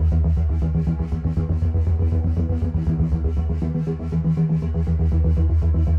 Index of /musicradar/dystopian-drone-samples/Tempo Loops/120bpm
DD_TempoDroneE_120-E.wav